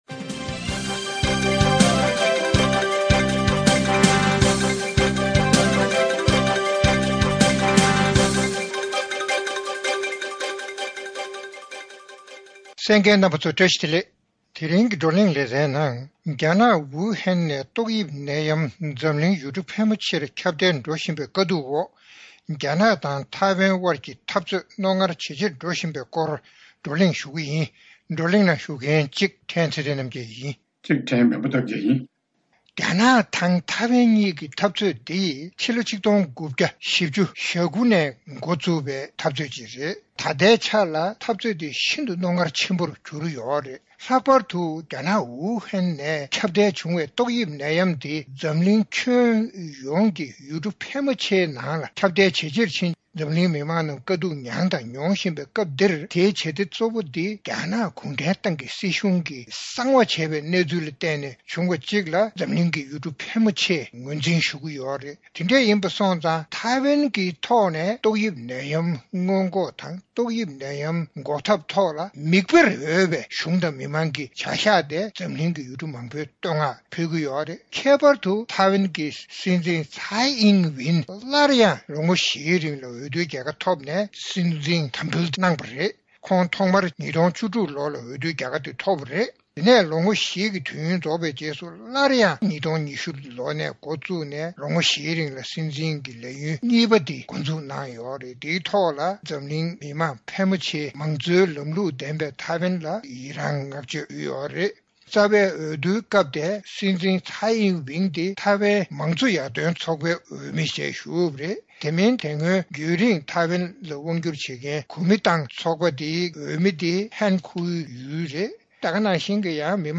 རྒྱ་ནག་དང་ཐེ་ཝན་དབར་འཐབ་རྩོད་རྣོ་ངར་ཇེ་ཆེར་འགྲོ་བཞིན་པའི་སྐོར་རྩོམ་སྒྲིག་པའི་གླེང་སྟེགས་ནང་བགྲོ་གླེང་གནང་བ།